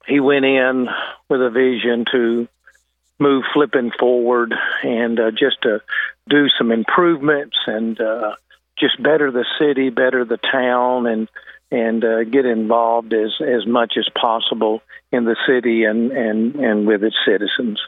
Hogan had praise for the job his predecessor did as Flippin’s top official.